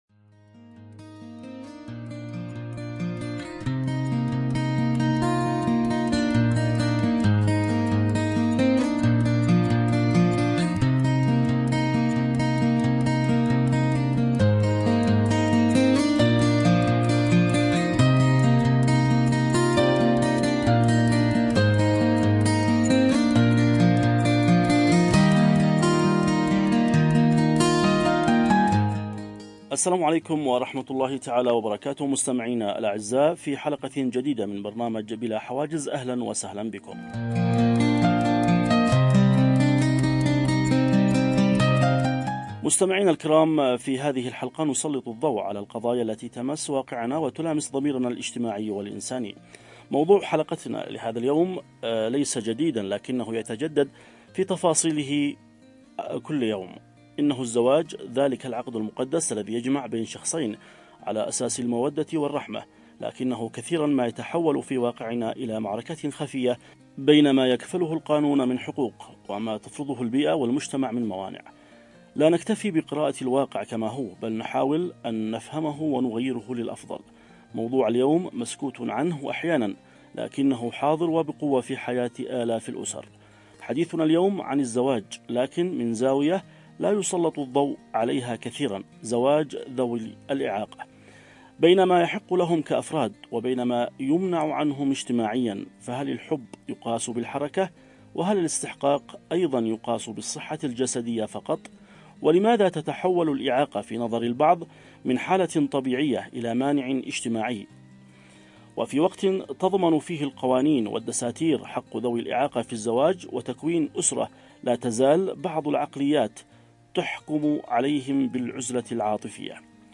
📅 الموعد: يوم السبت ⏰ الساعة: 11:00 صباحًا 📻 المكان: عبر أثير إذاعة رمز ندعوكم للمشاركة بآرائكم وتجاربكم عبر التعليقات و الرسائل الصوتية.